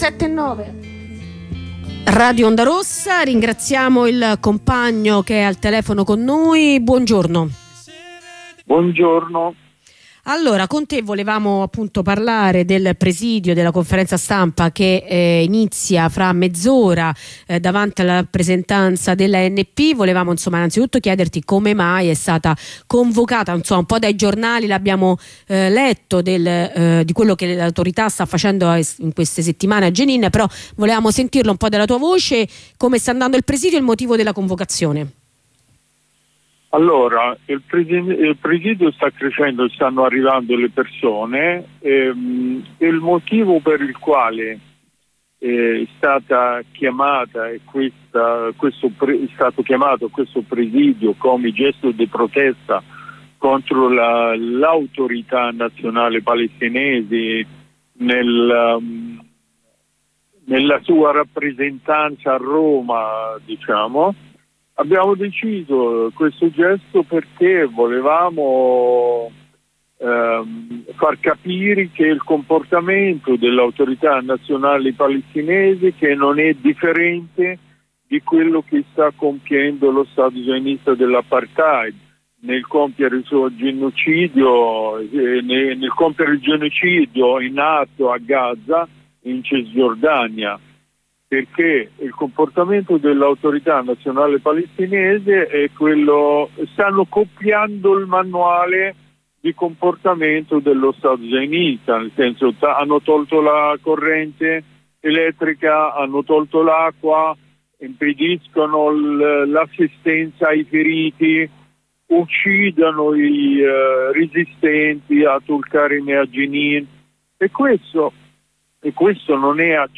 Ne parliamo con un compagno palestinese